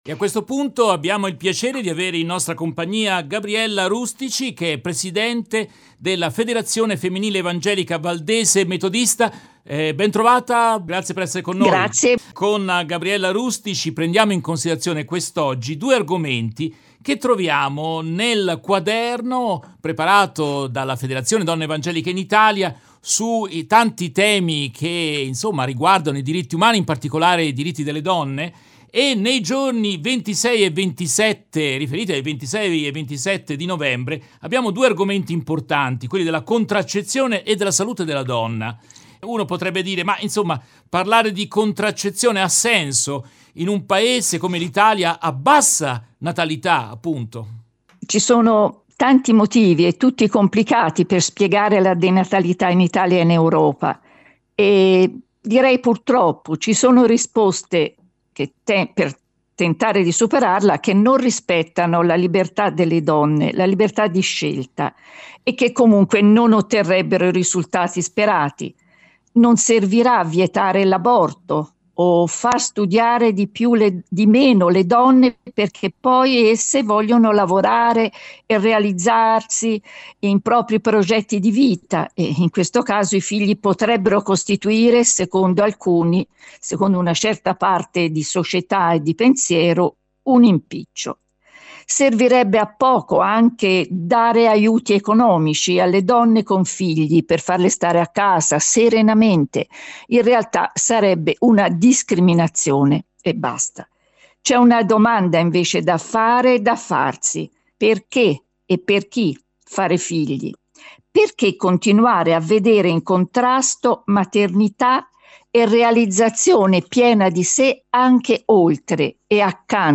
Oggi ascolteremo un'intervista